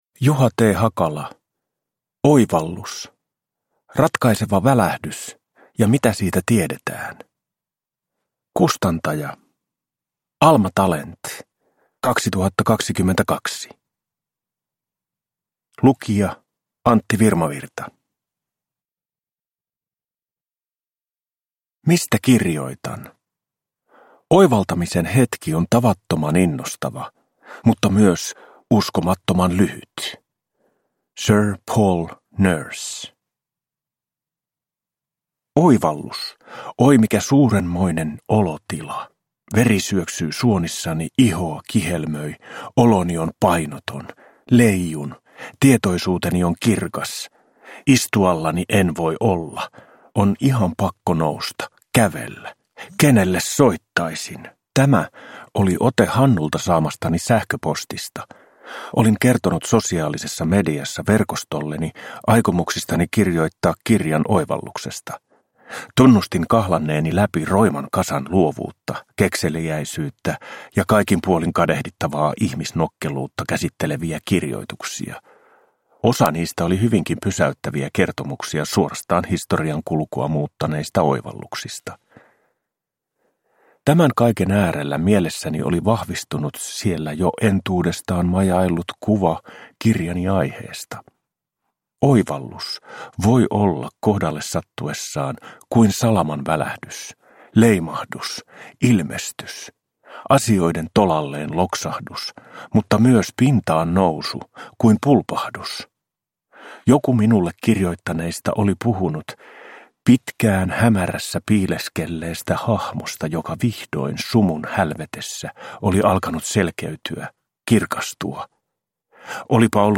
Oivallus – Ljudbok – Laddas ner